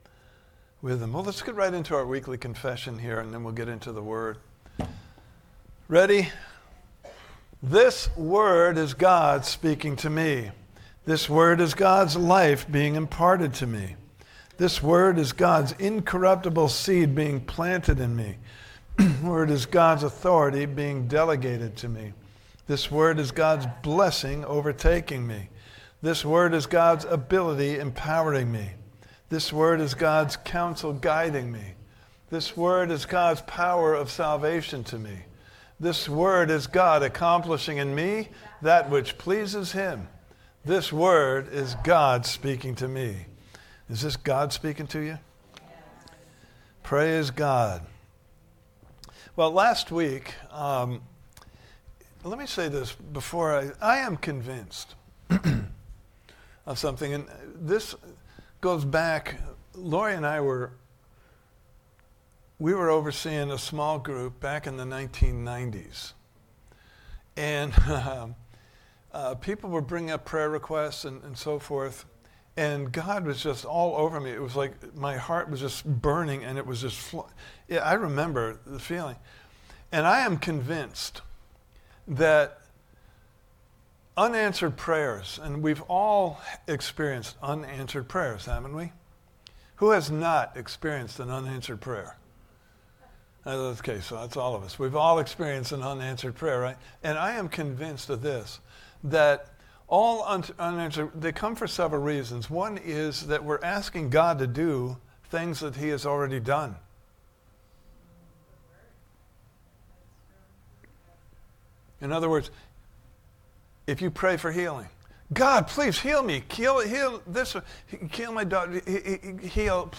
Series: The Art of Prayer Service Type: Sunday Morning Service